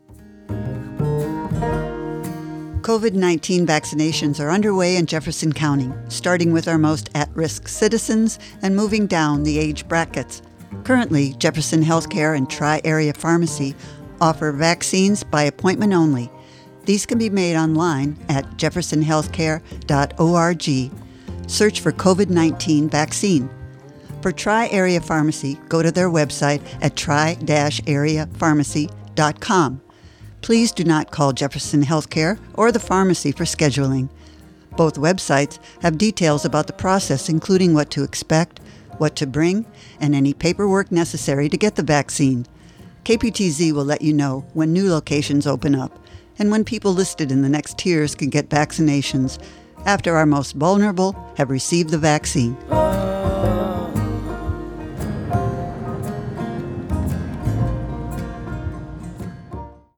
PSA-Covid-19-Vacinations.mp3